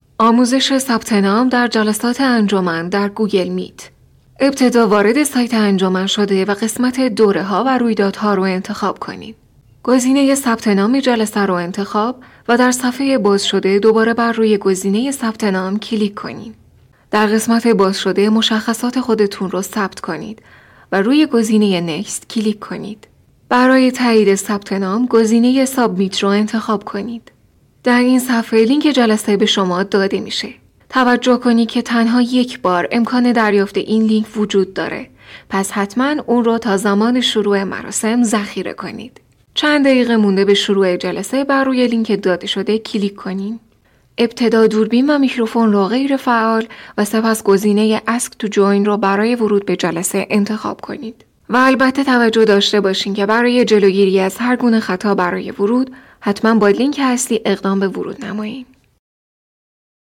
教育专题【知性优雅】